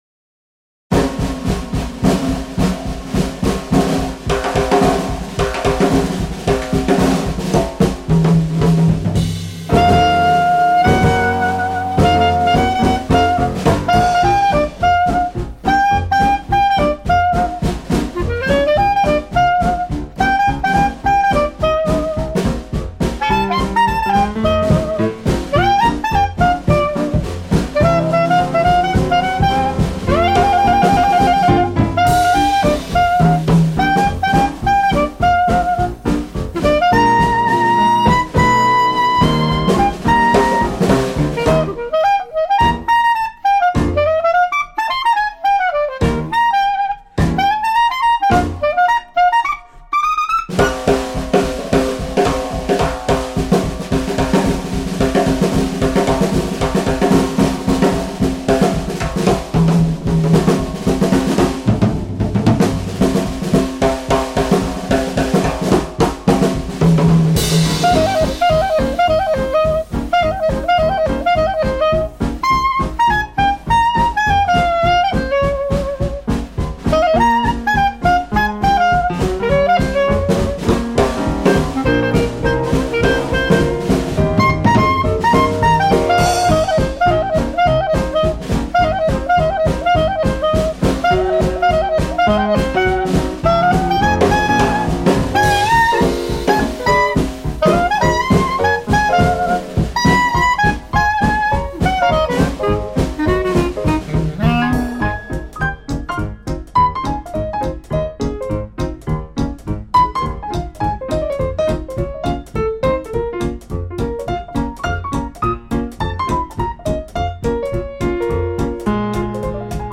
Si l’atmosphère Nouvelle Orléans règne